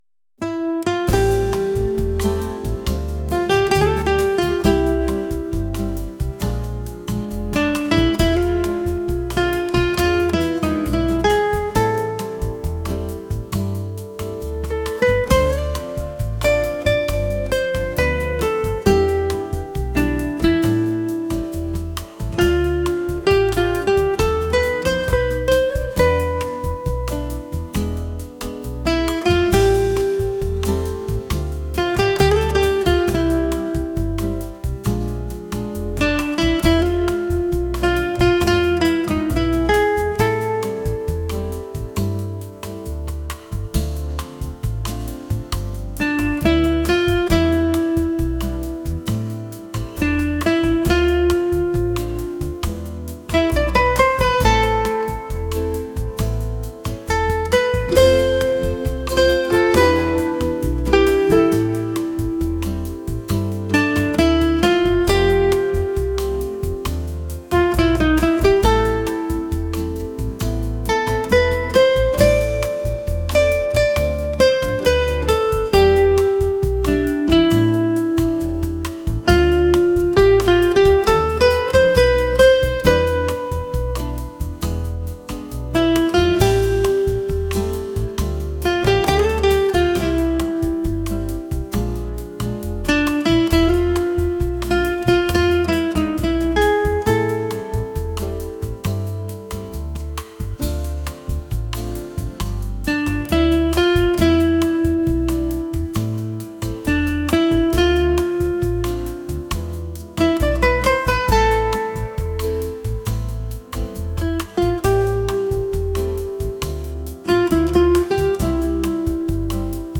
romantic | smooth